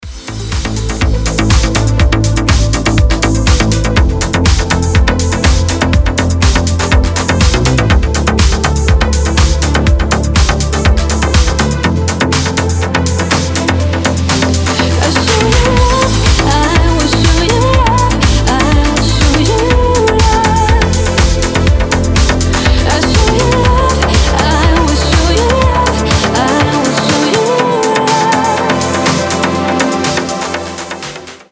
• Качество: 320, Stereo
громкие
женский вокал
deep house
nu disco
Indie Dance